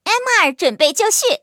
M2中坦编入语音.OGG